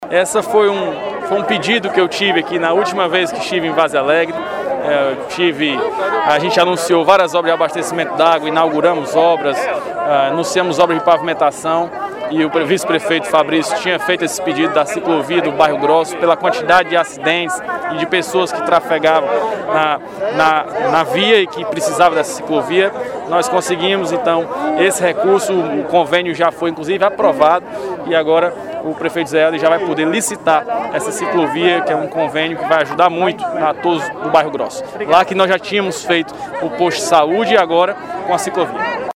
O anúncio ocorreu na inauguração da Base do Raio na cidade. Confira o áudio da entrevista: